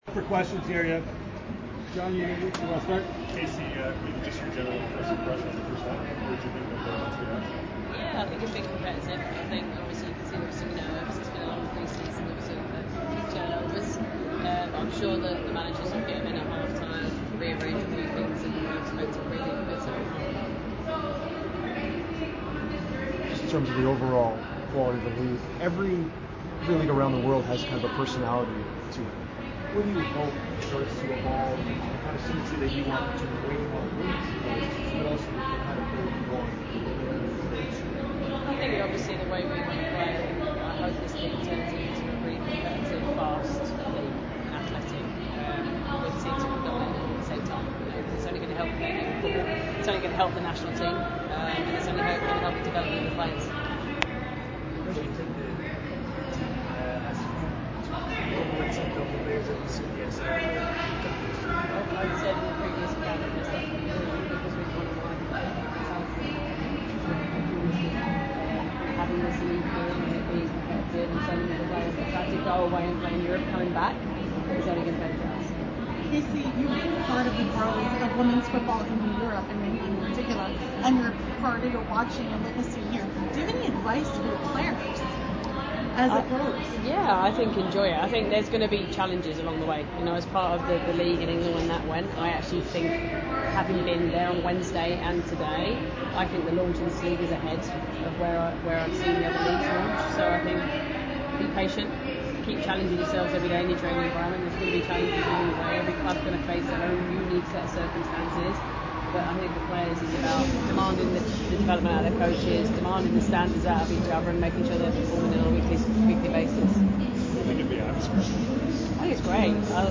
Most of the audio from the post game press conference after the NSL (regular season game) game
I didn't think the sound was good because the music and announcements from the field were pumping through the room because the first row reporters in the press box left the windows open but especially once I move, the answers are quite decipherable.
Questions asked to Casey Stoney and Kevin Blue